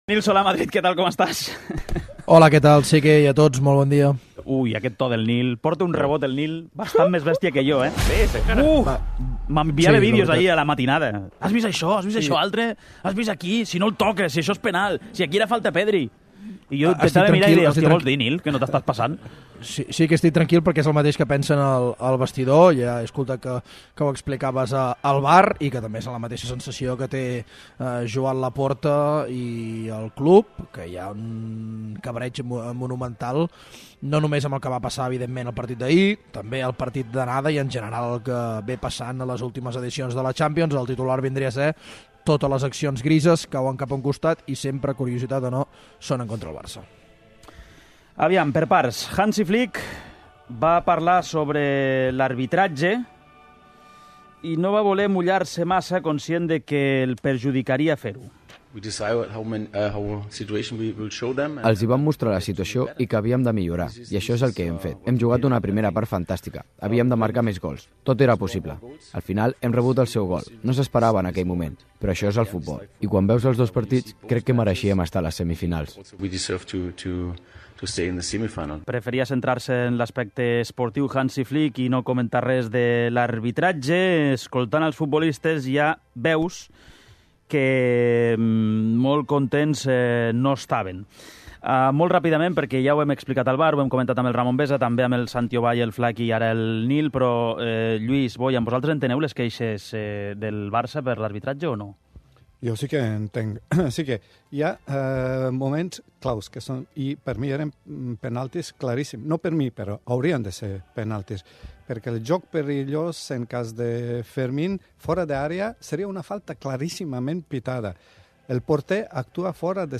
Al 'Què t'hi Jugues!' fem tertúlia de l'eliminació del Barça a Champions amb Bojan Krkić i Lluís Carreras